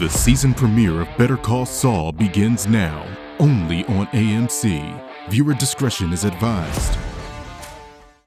TV Promo Samples
Exciting
TV-Promo-Demo_Better-Call-Saul.mp3